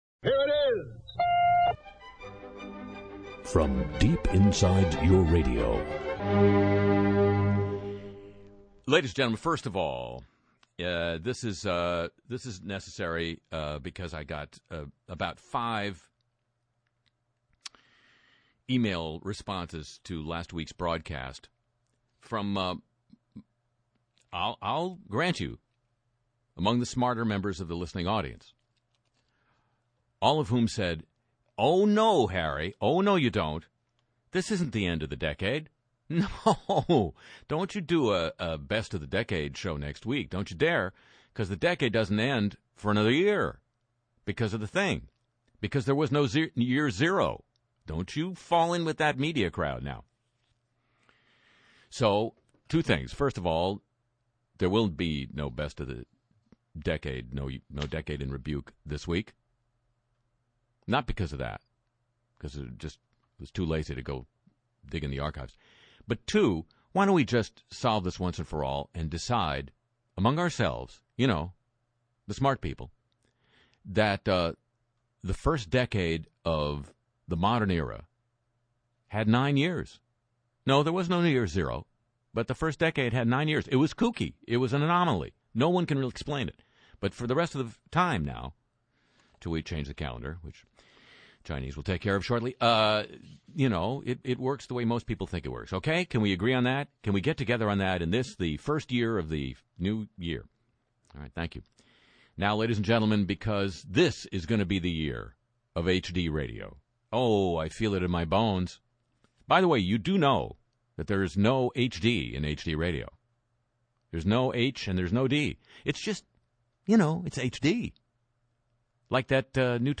NY3, live from Times Square